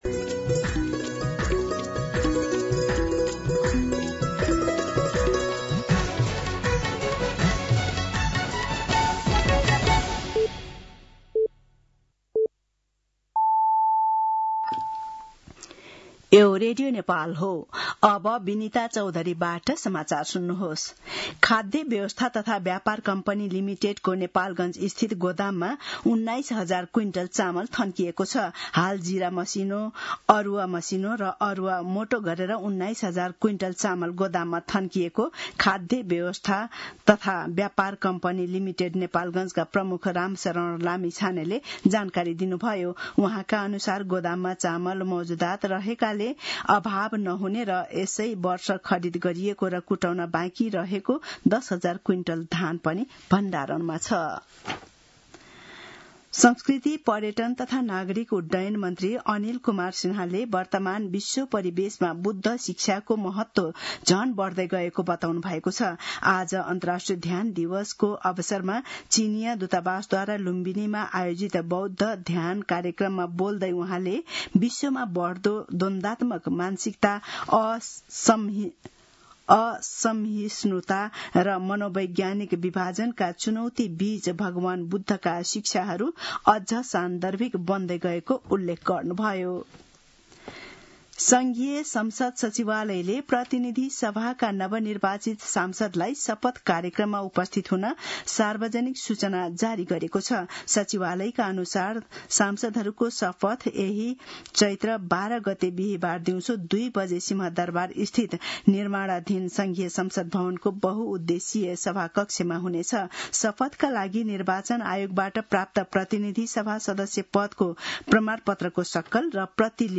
दिउँसो १ बजेको नेपाली समाचार : ६ चैत , २०८२